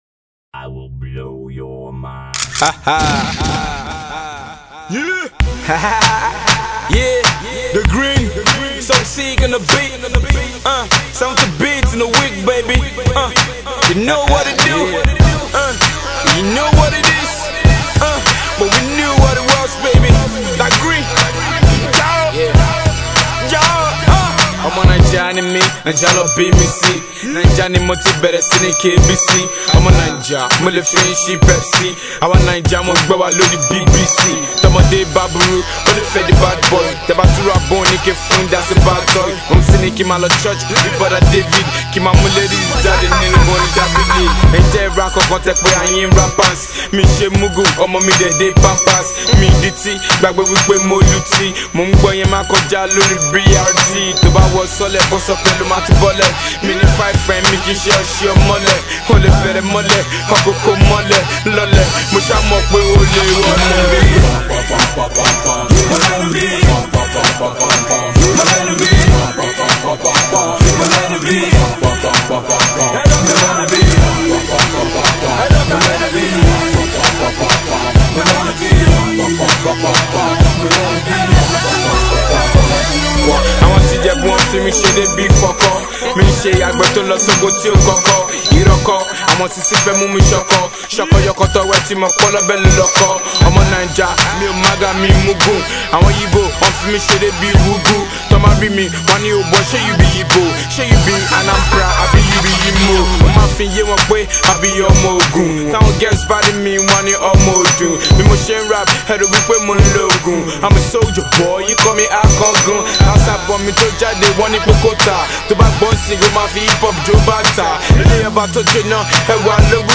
Indigineous Yoruba Rapper